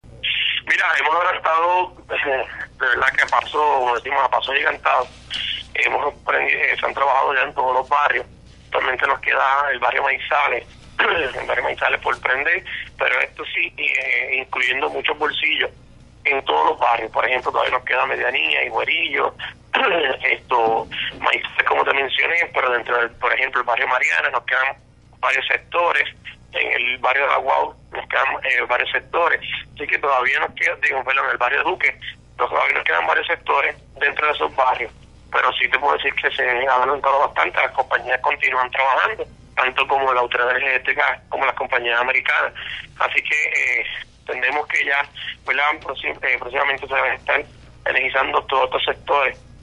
Alcalde de Naguabo, Noé Marcano Rivera